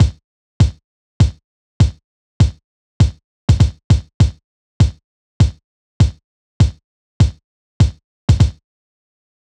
Unison Funk - 3 - 100bpm - Kick.wav